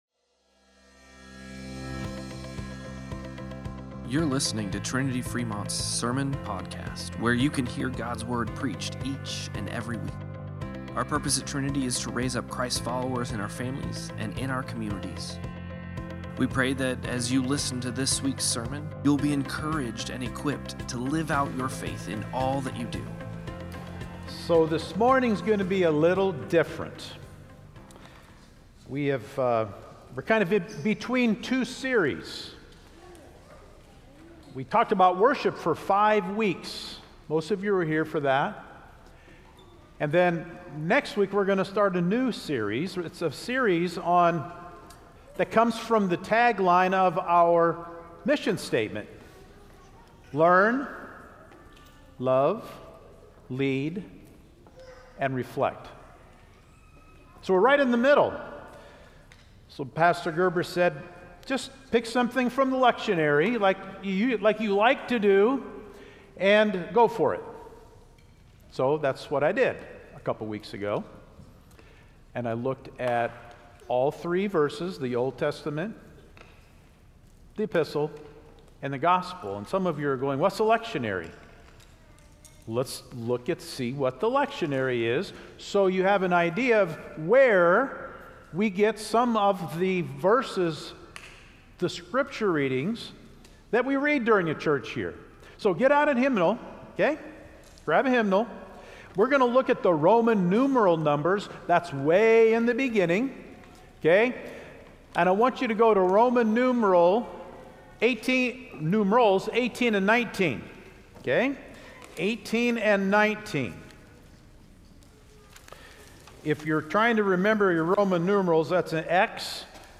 08-31-Sermon-Podcast.mp3